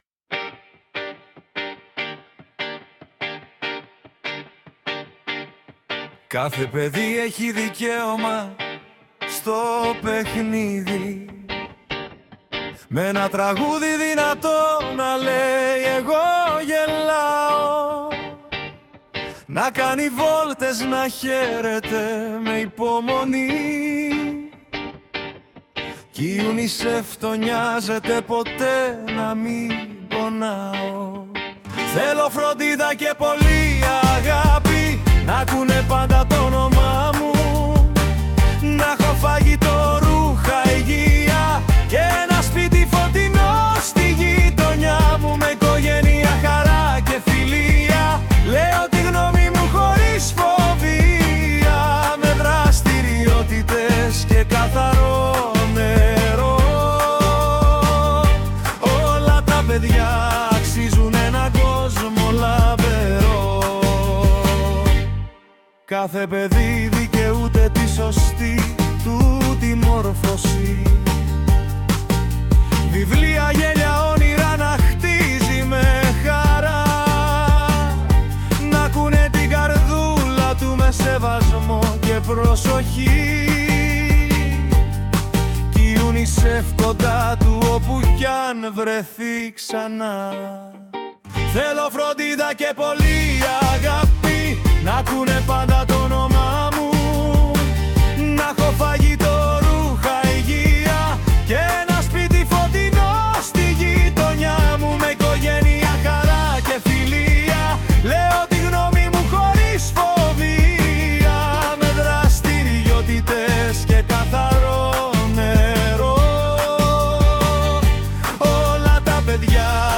Τραγούδια που δημιουργήσαμε με τη βοήθεια της Τεχνητής Νοημοσύνης (ChatGpt, Suno)